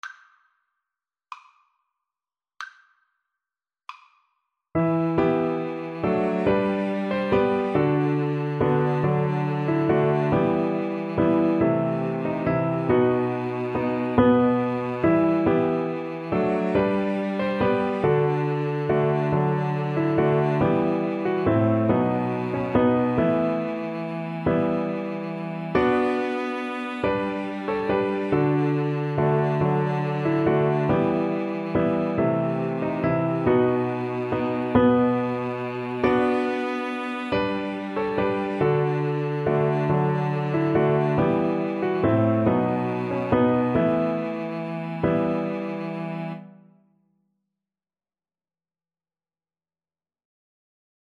ViolinCelloPiano